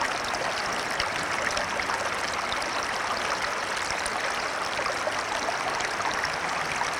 stream-short.wav